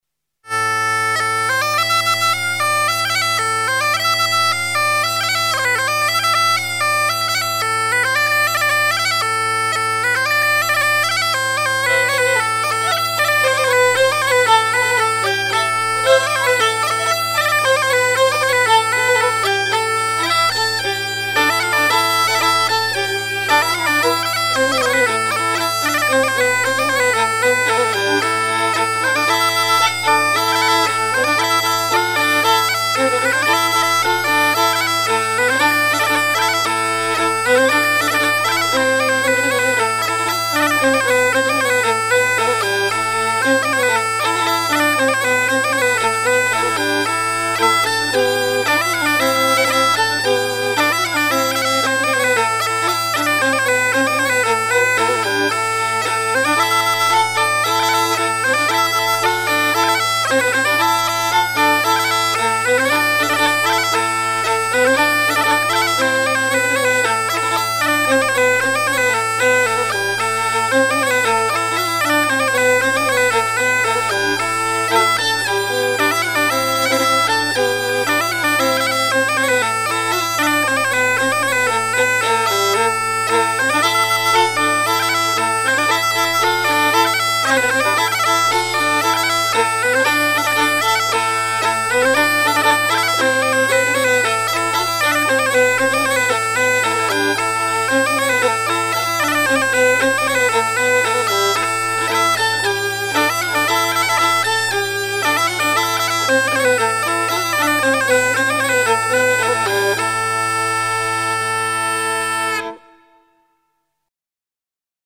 danse : matelote
Pièce musicale éditée